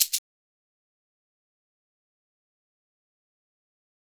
Index of /90_sSampleCDs/USB Soundscan vol.56 - Modern Percussion Loops [AKAI] 1CD/Partition D/04-SHAKER119